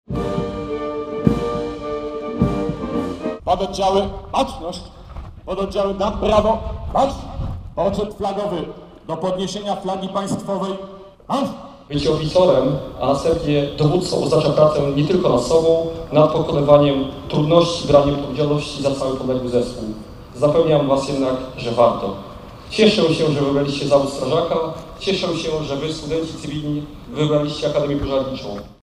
Akademia Pożarnicza rozpoczęła rok akademicki.
Przemowę wygłosił rektor i komendant uczelni nadbrygadier doktor inżynier Mariusz Feltynowski.